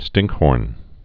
(stĭngkhôrn)